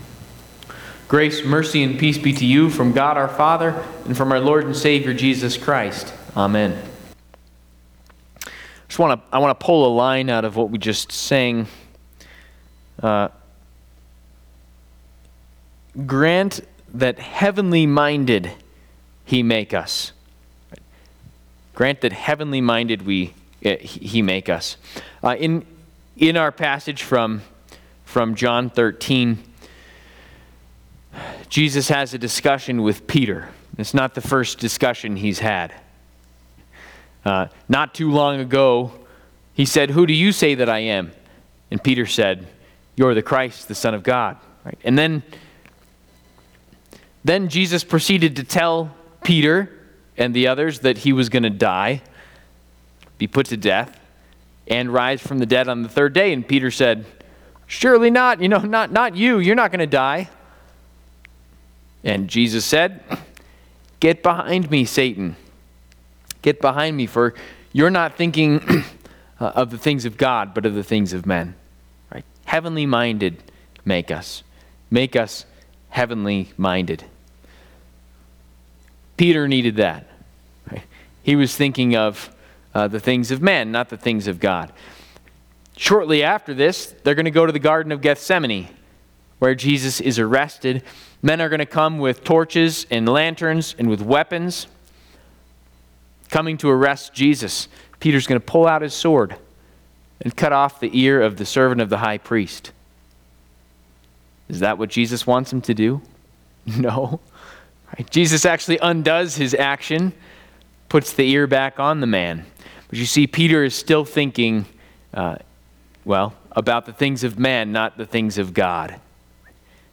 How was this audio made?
Maundy Thursday&nbsp Trinity Lutheran Church, Greeley, Colorado Washed by Jesus Apr 17 2025 | 00:13:22 1x 00:00 / 00:13:22 Subscribe Share RSS Feed Share Link Embed